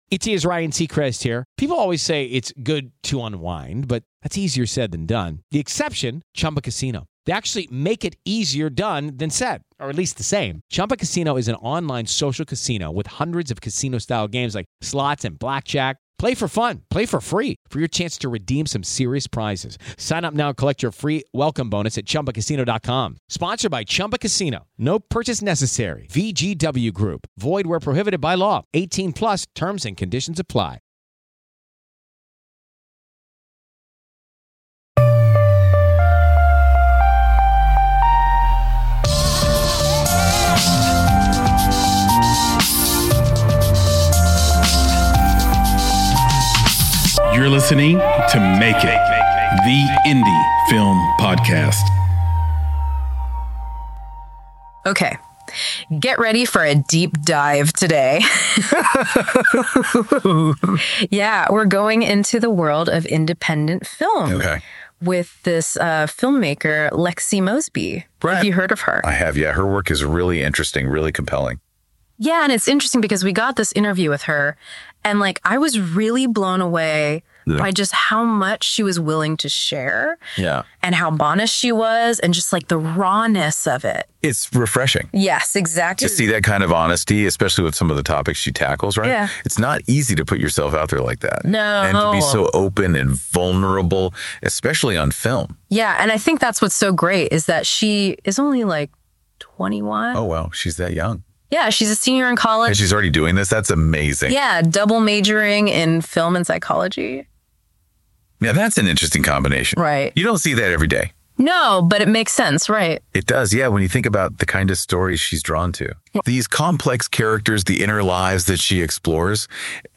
Welcome to SUMMARIES, Episode #1 Summaries takes some of our best long-form interviews (and even some unpublished ones) and condenses them to down to 10 minutes of pure listening pleasure. Summaries are hosted by our digital interns